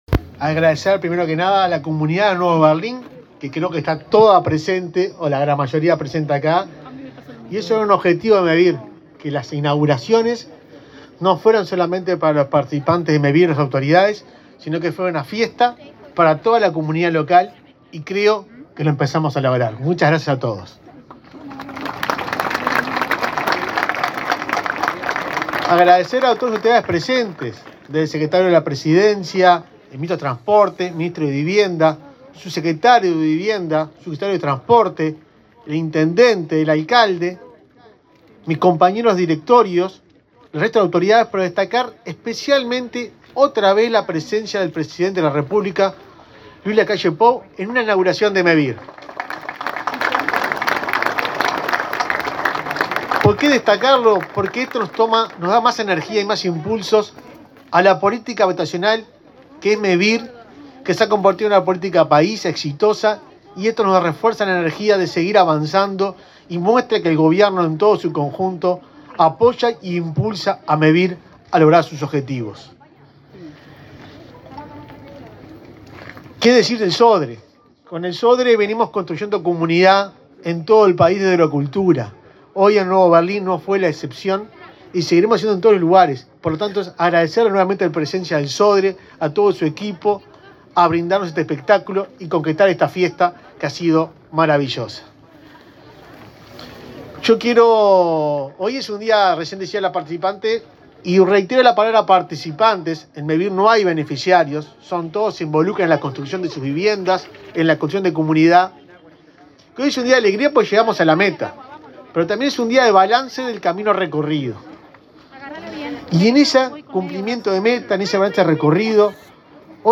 Palabras de autoridades en inauguración de Mevir
Palabras de autoridades en inauguración de Mevir 29/06/2023 Compartir Facebook X Copiar enlace WhatsApp LinkedIn El presidente de Mevir, Juan Pablo Delgado, y el ministro de Vivienda, Raúl Lozano, participaron, este jueves 29, en la inauguración de 63 soluciones habitacionales en la localidad de Nuevo Berlín, departamento de Río Negro.